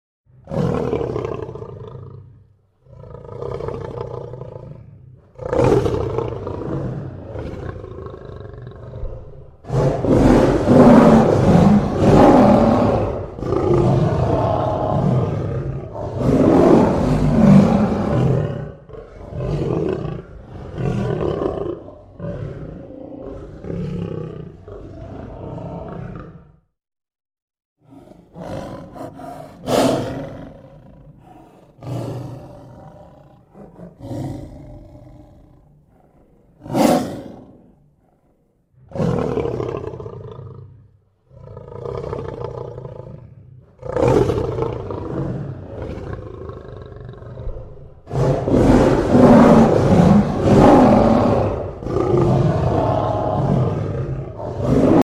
Lion Roaring Bouton sonore
The Lion Roaring sound button is a popular audio clip perfect for your soundboard, content creation, and entertainment.